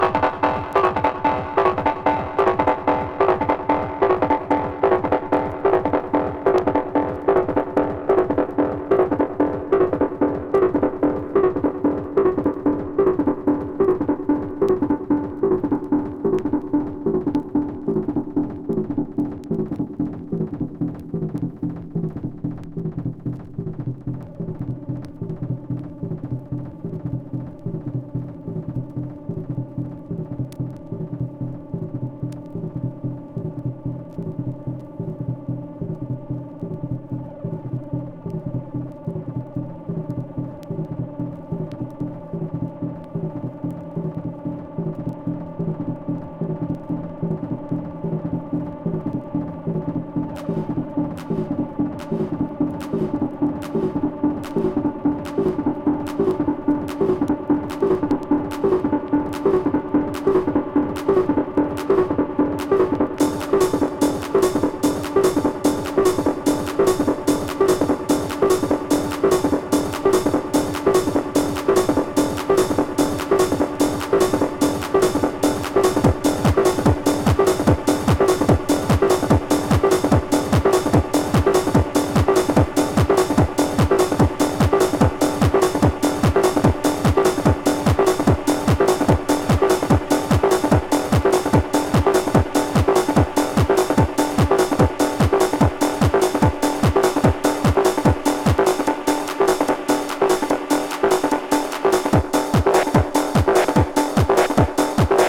シンプルで力強いミニマルトラック